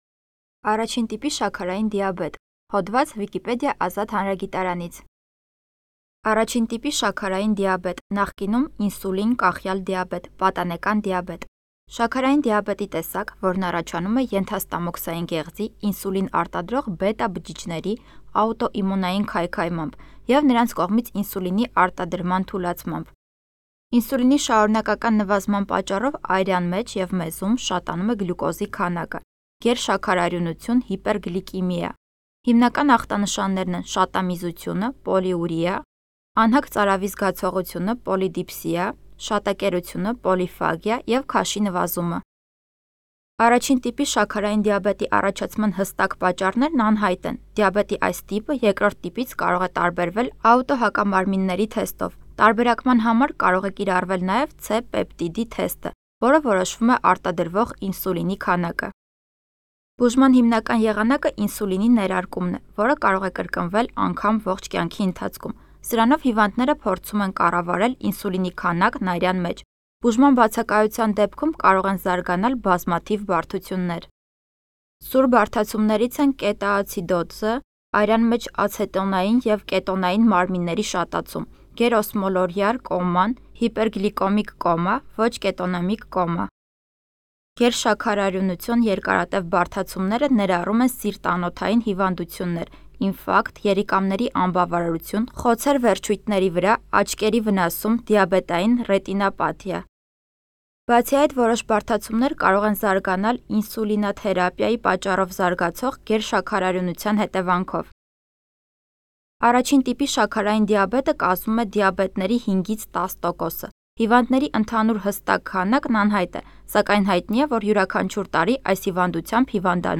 동아르메니아어 발음